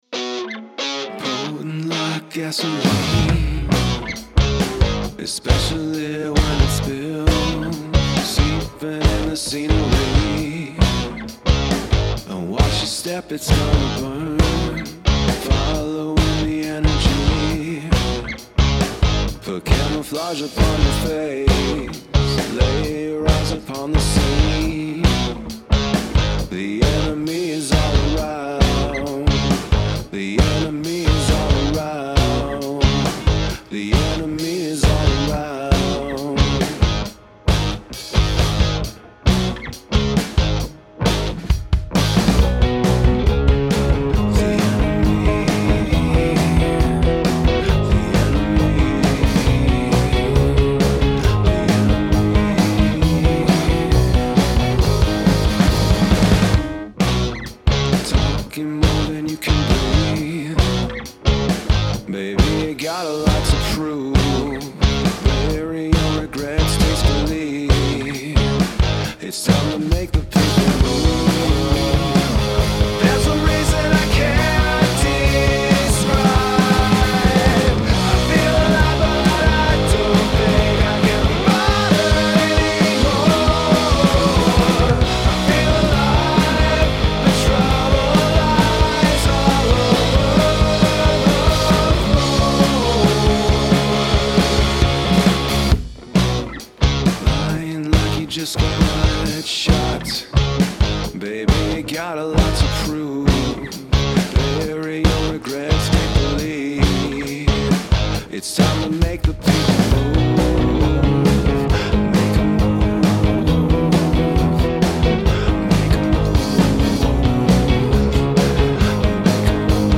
dark, nervous punk rock
Lincoln Park, New Jersey